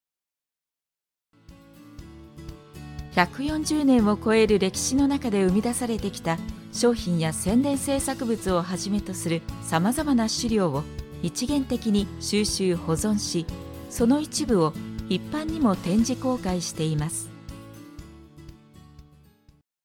– Narration –
Gentle